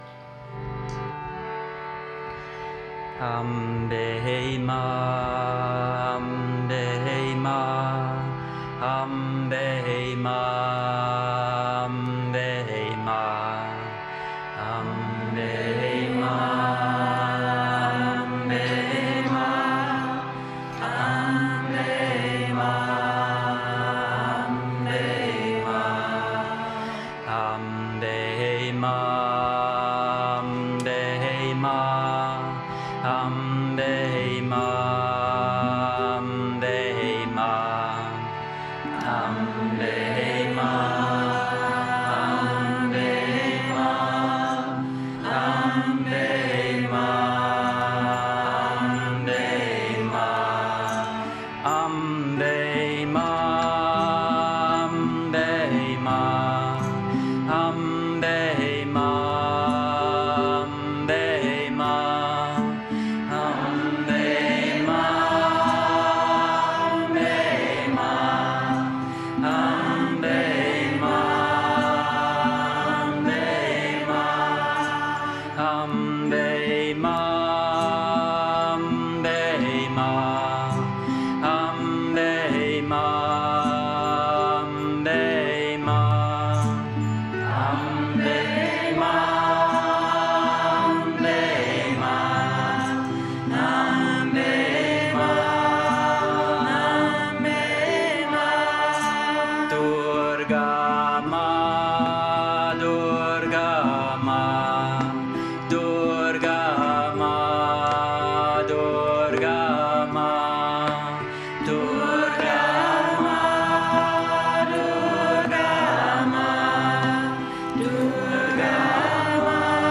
Mantra Singen, Kirtan